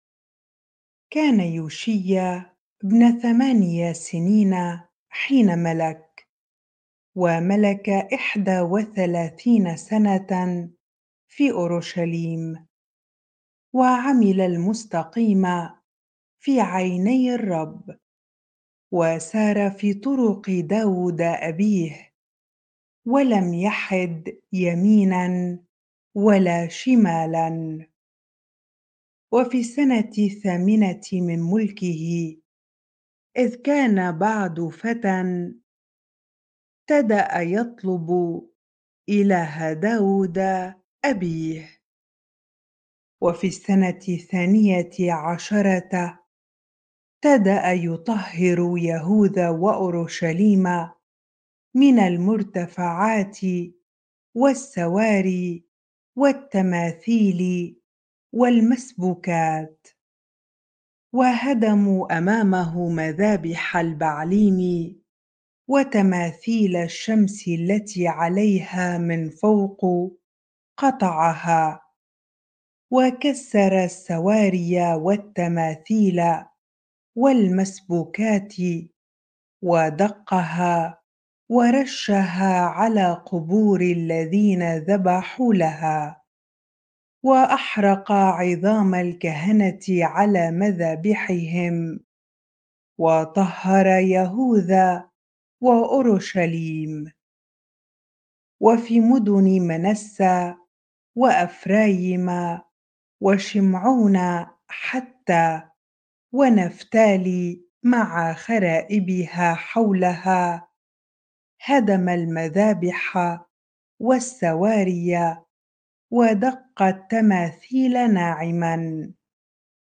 bible-reading-2 Chronicles 34 ar